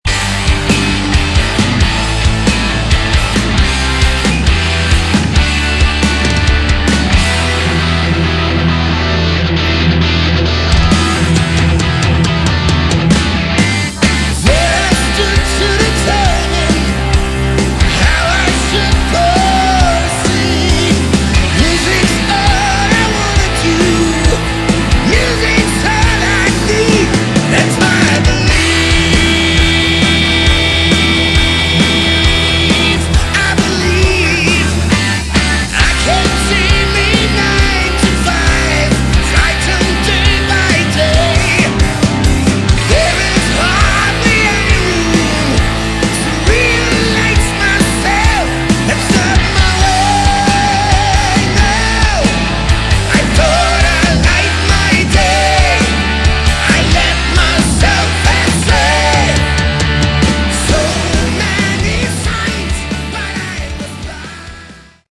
Category: Melodic Metal
vocals
lead guitars
rhythm guitars
bass
drums